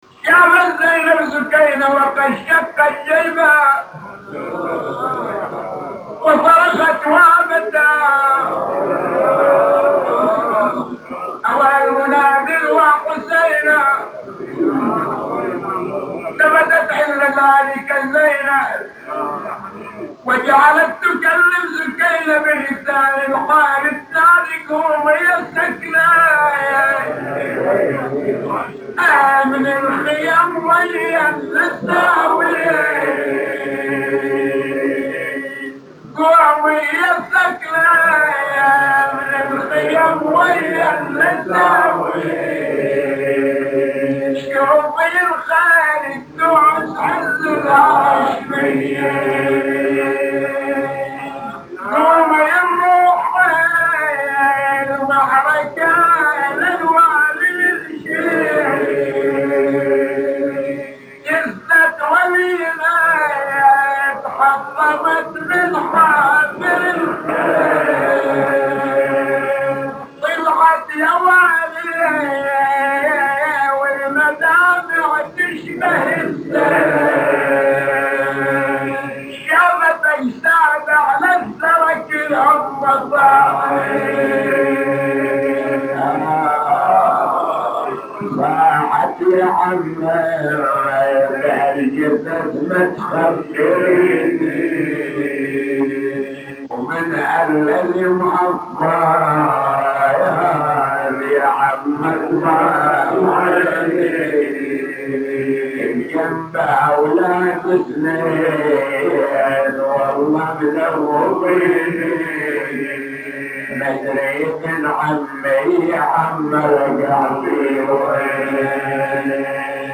نواعي وأبيات حسينية – 14